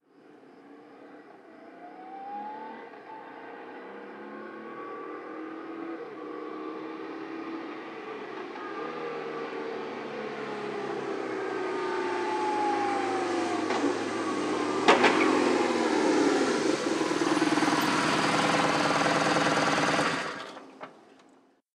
Moto marca BMW llega y para rápido
motocicleta
Sonidos: Transportes